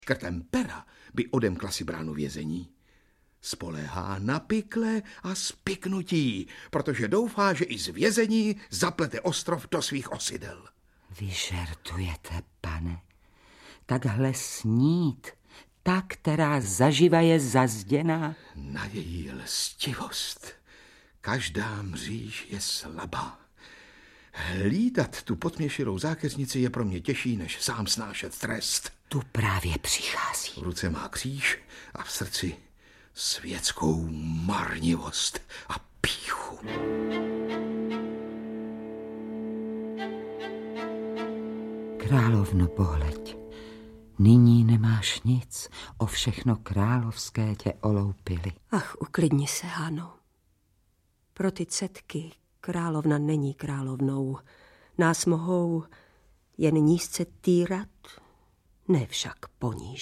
Audiobook
Read: Jaromír Meduna